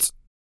Index of /90_sSampleCDs/ILIO - Vocal Planet VOL-3 - Jazz & FX/Partition H/1 MALE PERC
HIHAT 01.wav